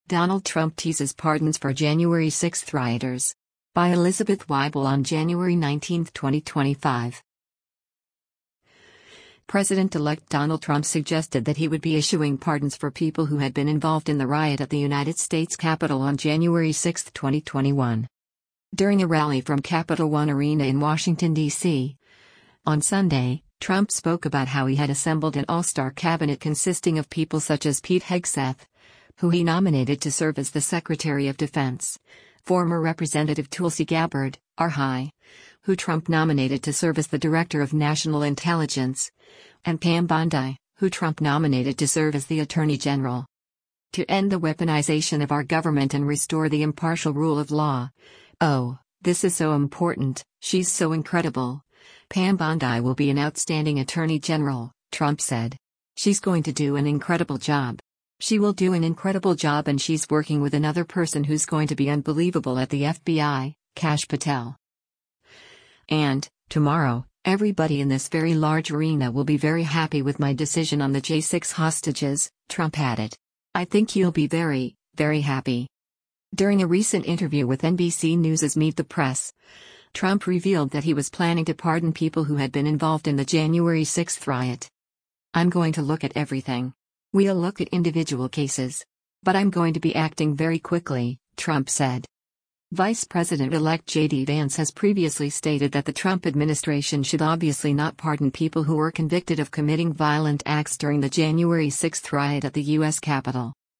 President-elect Donald Trump speaks at a rally ahead of the 60th Presidential Inauguration